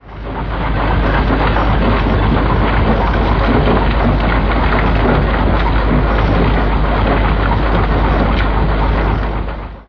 ore-sorting-facility.ogg